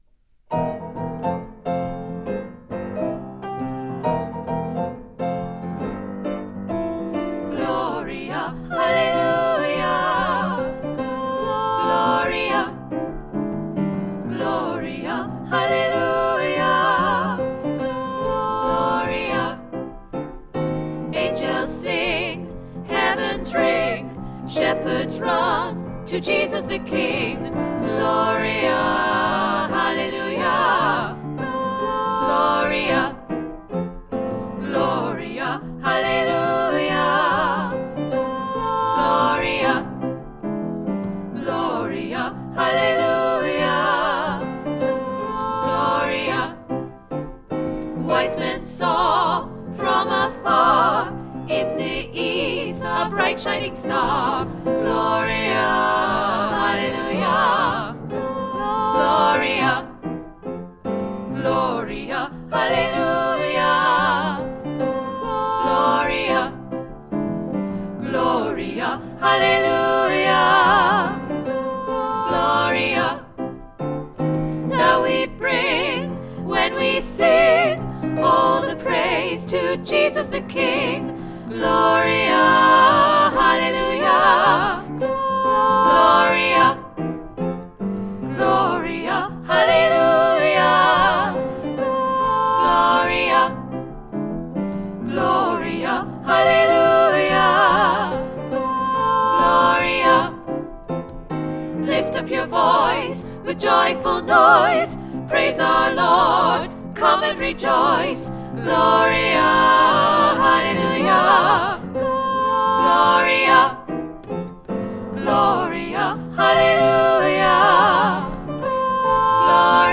Gloria Hallelujah SATB